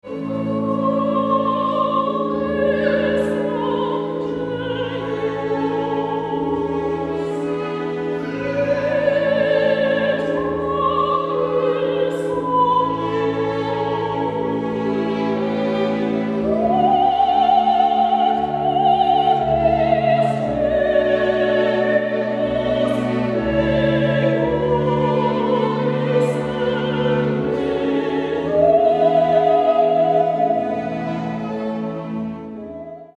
Festliche Streicherklänge zur Zeremonie
(Besetzung D: Streichquartett, SolosängerIn und Orgel)